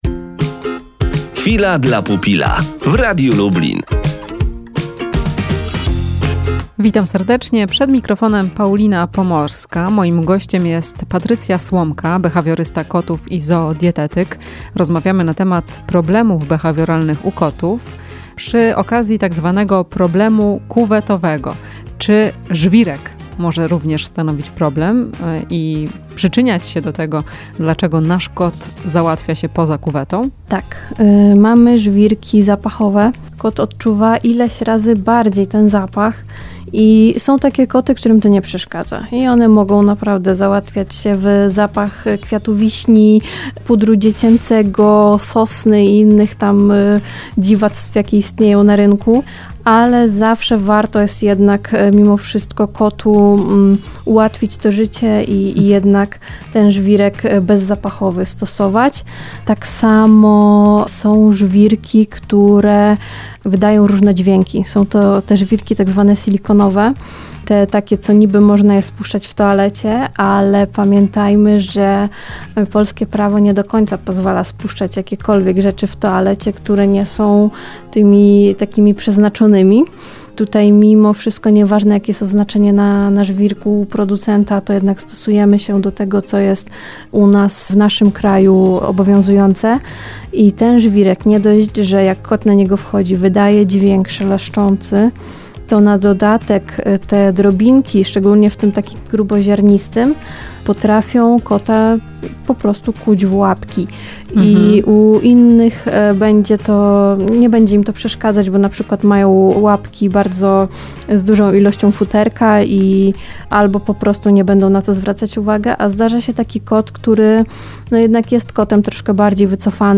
Rozmowa z behawiorystą kotów i